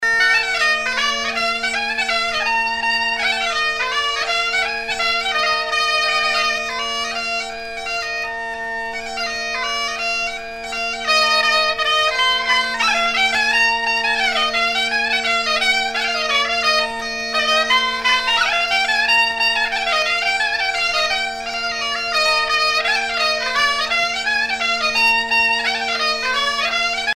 Fonction d'après l'analyste danse
Pièce musicale éditée